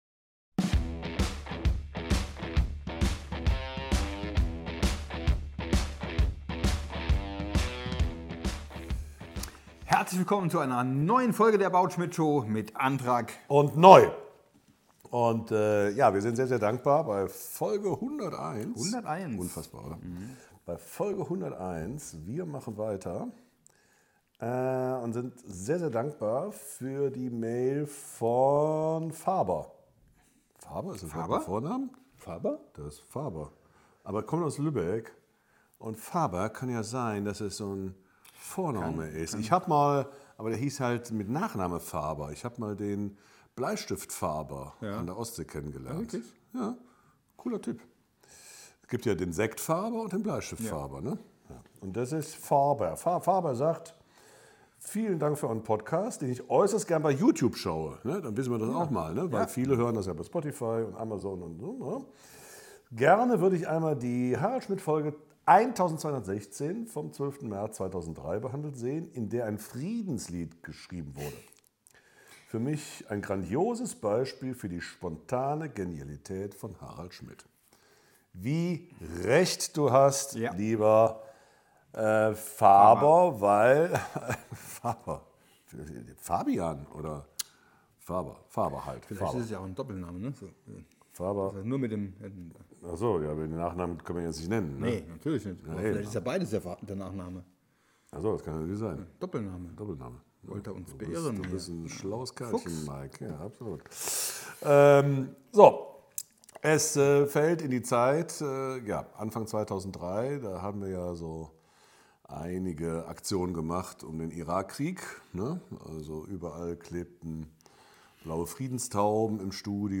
Sein unermüdlicher Einsatz für den Weltfrieden kulminiert in einem genialen Friedenssong, den er mit Hilfe des Studiopublikums und der Band von Helmut Zerlett komponiert. Eine großartige Stegreif-Leistung, ein Improvisations-Konzert der Extra-Klasse. Manuel Andrack beweist, dass er nicht nur schief singen kann, sondern hinten raus wie ein Heldentenor performed.